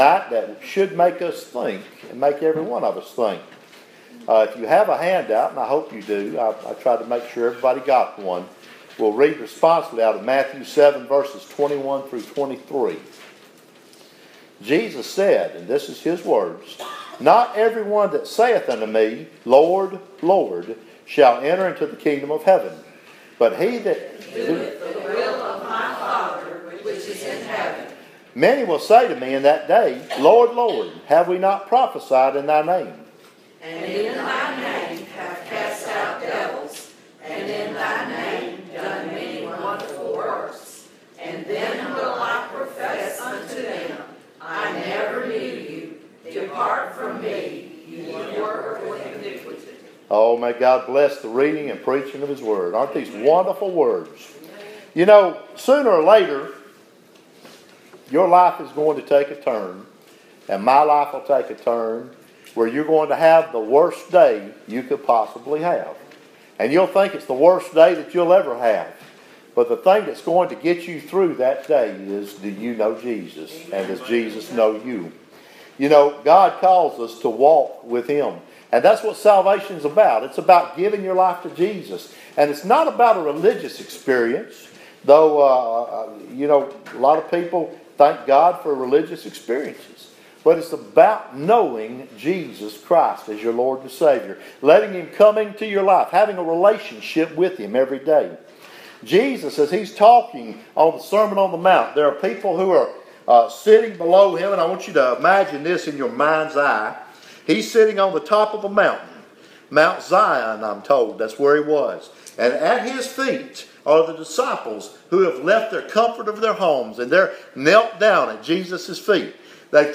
Preached to the Saints at Riverview Baptist on February 19, 2017 at 798 Santa Fe Pike, Columbia, TN 38401